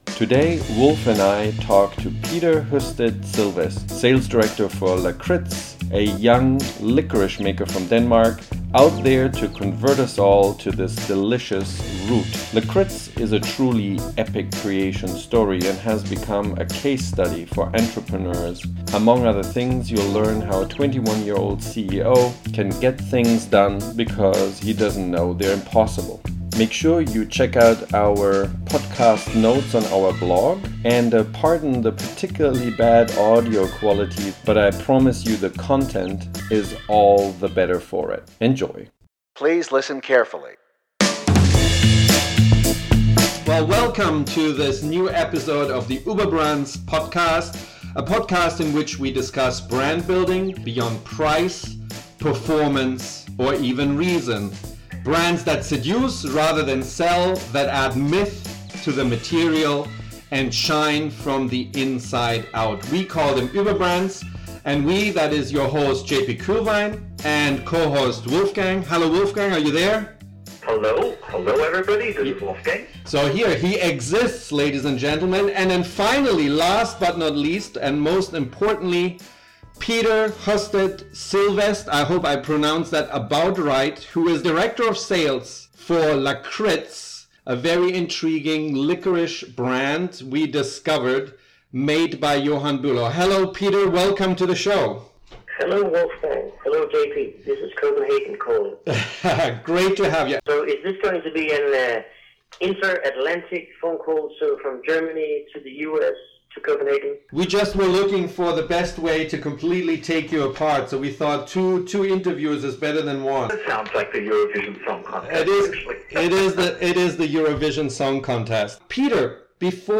Finally, there are few extra minutes of insightful chat after the regular half-hour interview, if you want to hang around.